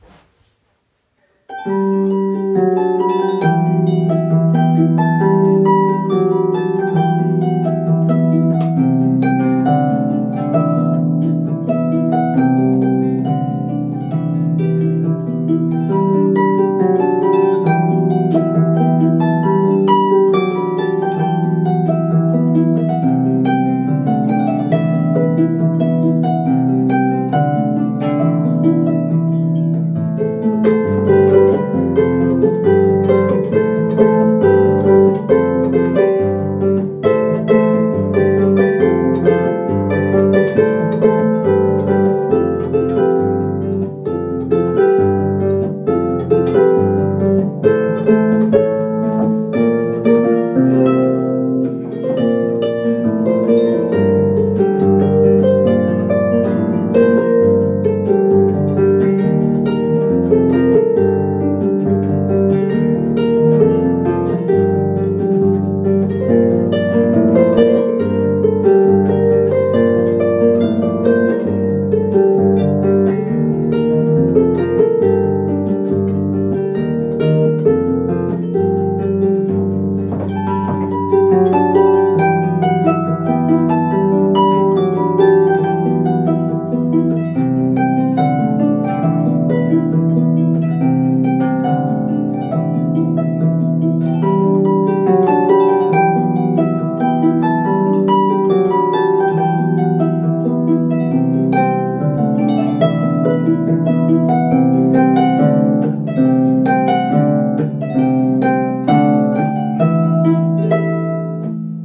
Pedal harp version: